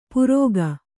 ♪ purōga